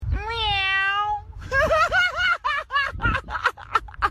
Cat Man Voice Botão de Som